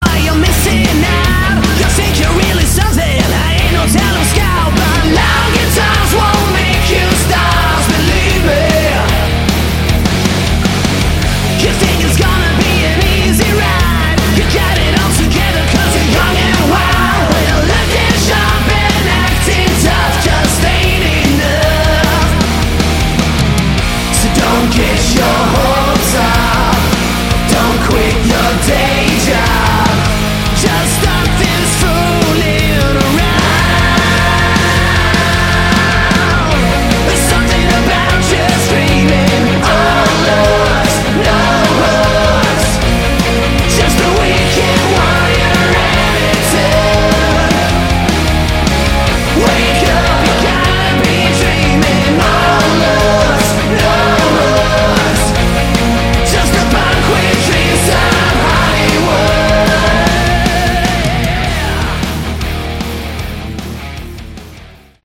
Category: Hard Rock
guitar, backing vocals